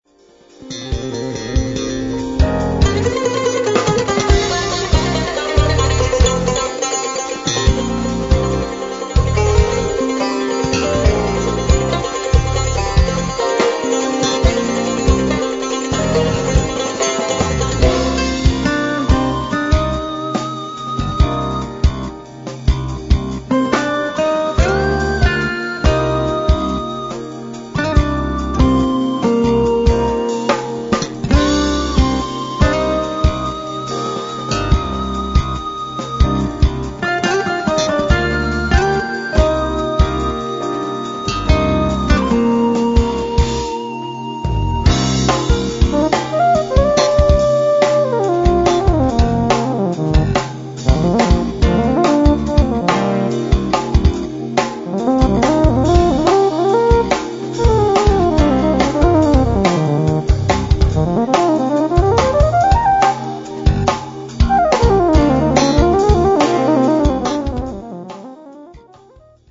ハンガリーのトップ・エスノ・ジャズ・ロック・バンド、４年ぶりとなる待望の2012スタジオ盤
drums
bass guitar, synths, guitar (8, 10), percussion
trumpet, violin
keyboards
vocal and guitar
tar
tenor sax
cimbalom
shepherd flute